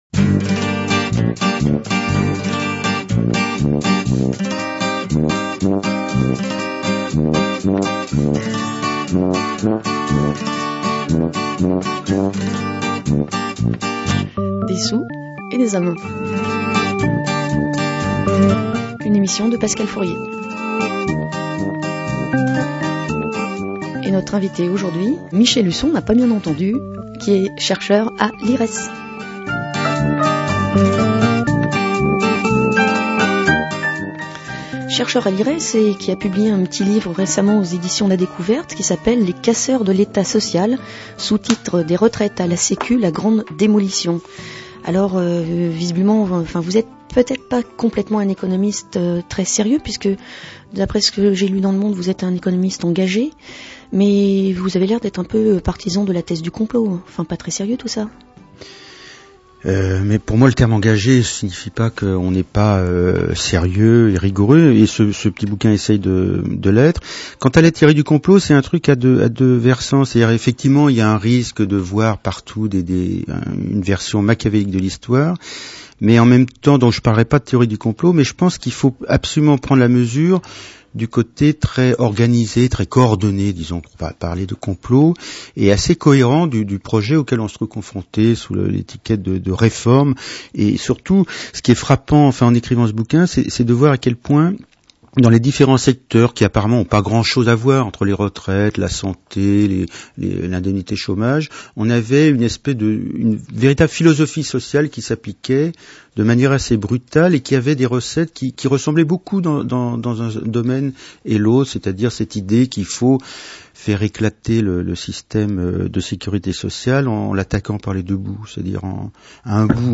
L’émission radiophonique (au format mp3)